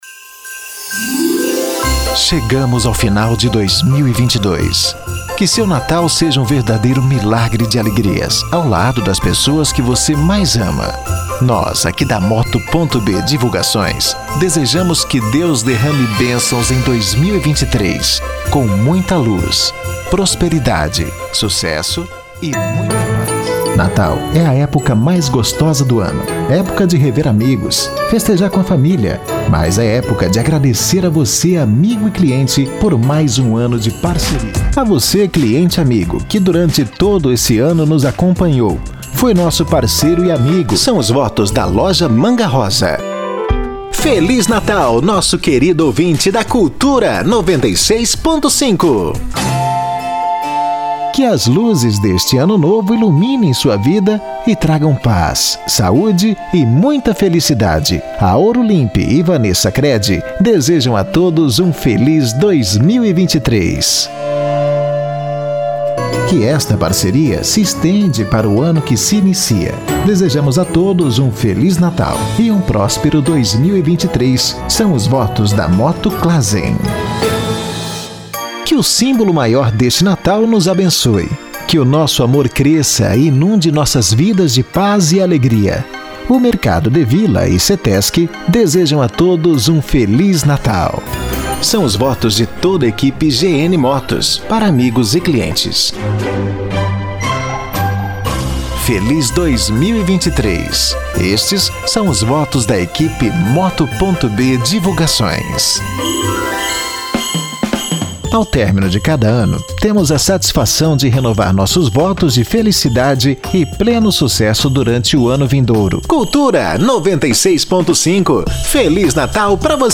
MENSAGENS DE FINAL DE ANO (NATAL E ANO NOVO):